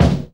JAZZ KICK 3.wav